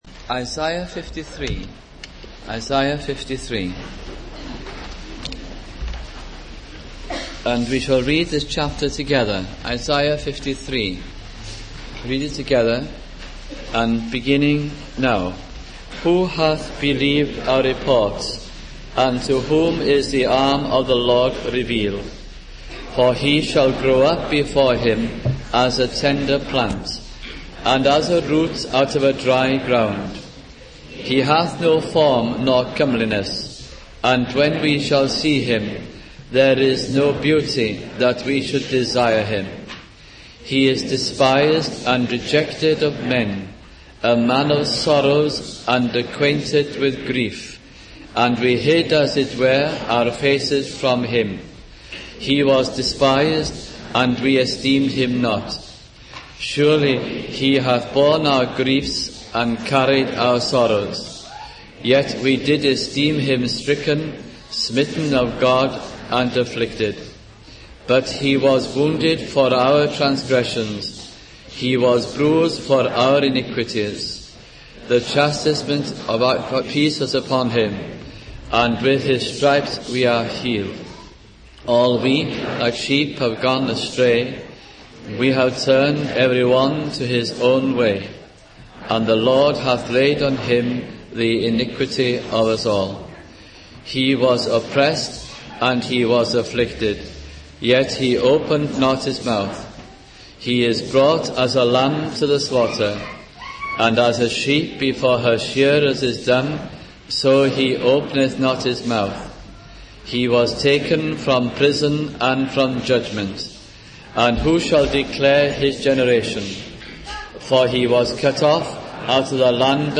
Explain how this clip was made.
Title: Good Friday Whole Service